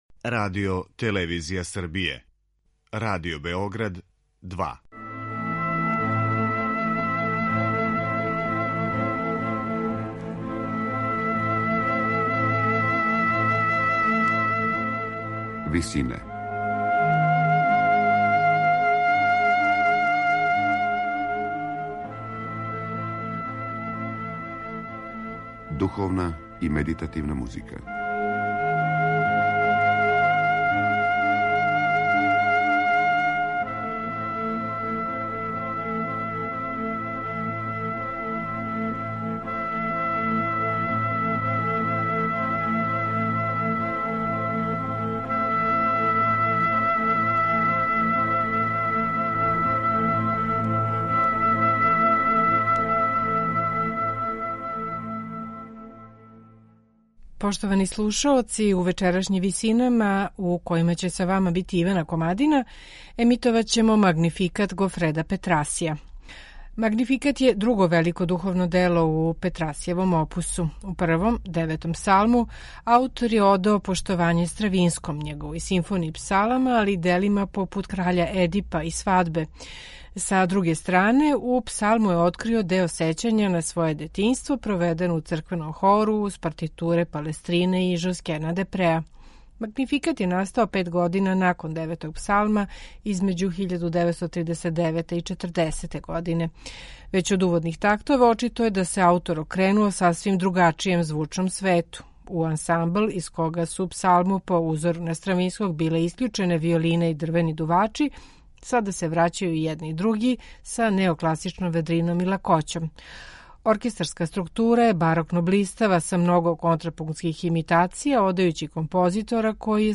сопран